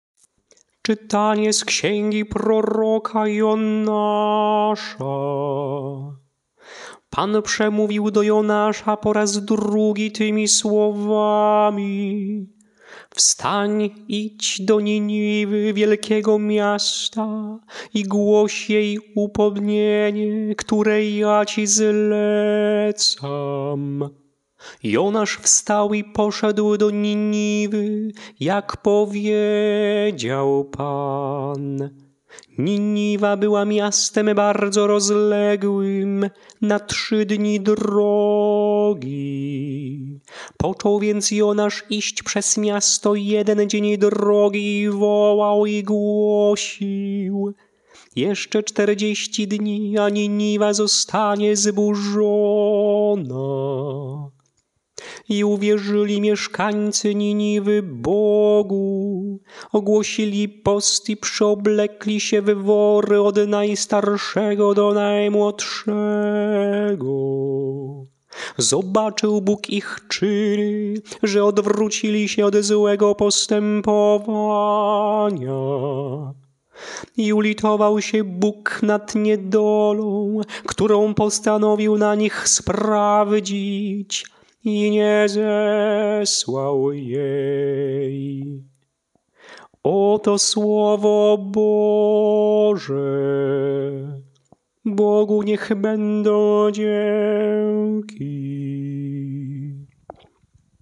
Śpiewane lekcje mszalne – IV Niedziela Zwykła
Melodie lekcji mszalnych przed Ewangelią na IV Niedzielę Zwykłą: